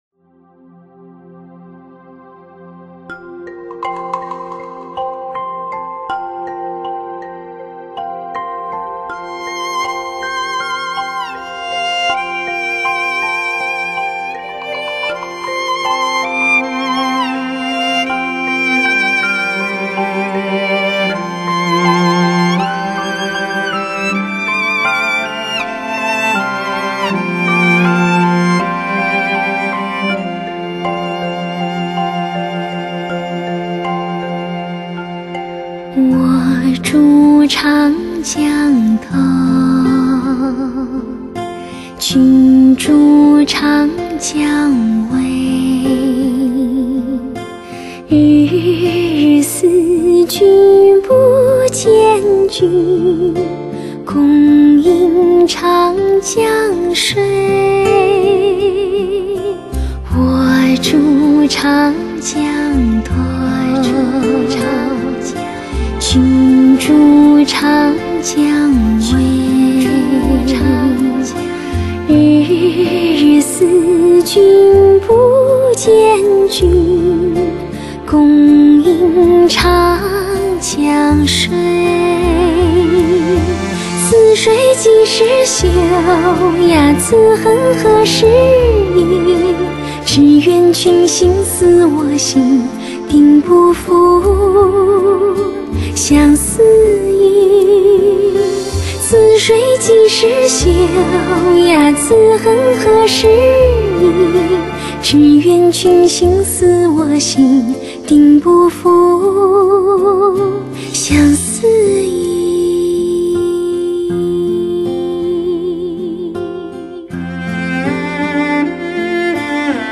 发烧界最柔美的天籁女声，极致的超模拟HI-F1发烧录音。
尽显高贵典雅的人声独特气质，婉约凄迷的演绎。
无限悠长的情感与神韵融合一体。